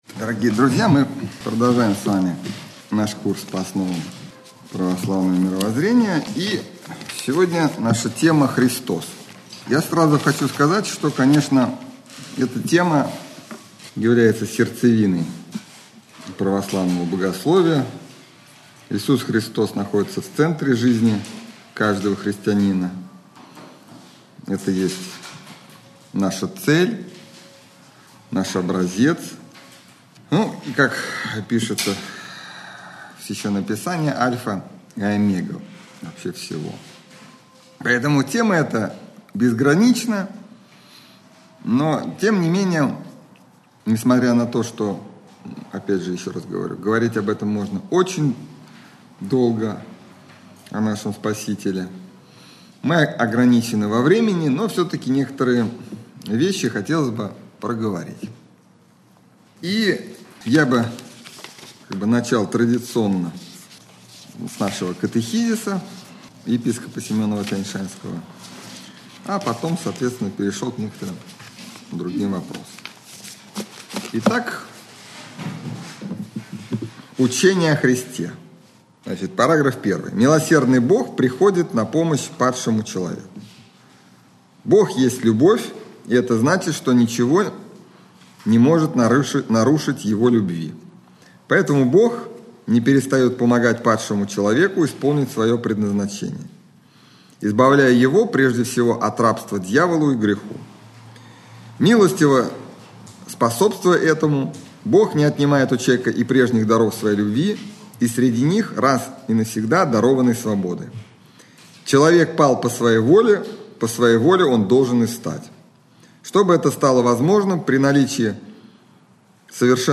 лекция
Общедоступный православный лекторий 2013-2014